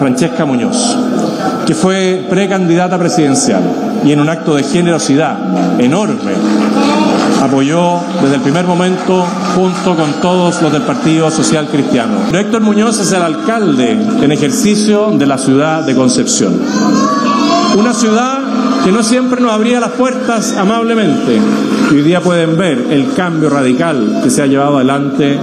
En su primer discurso como presidente electo, José Antonio Kast nombró de forma explícita no solo al Partido Social Cristiano, sino que al Matrimonio Muñoz – Muñoz, compuesto por la diputada, Francesca Muñoz, y el alcalde de Concepción, Héctor Muñoz, a quienes manifestó su agradecimiento.